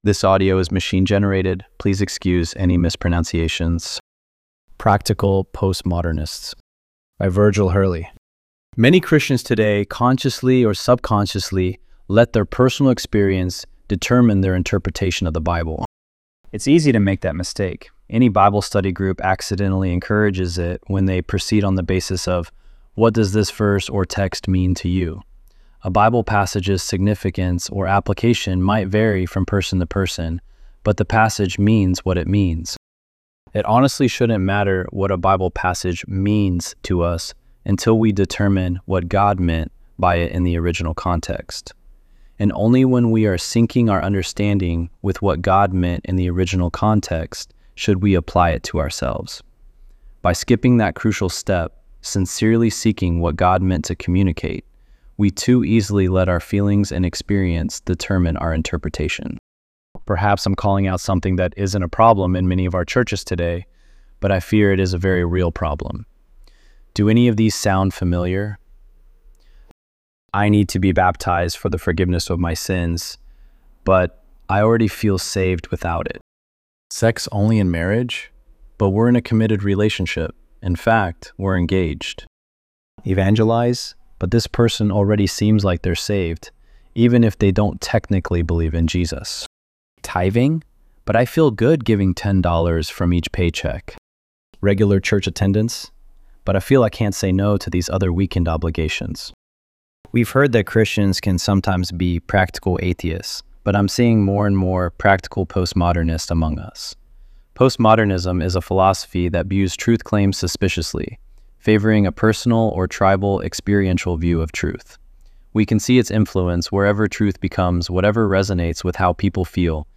ElevenLabs_Untitled_project-3.mp3